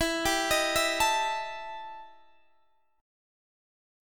EmM7bb5 Chord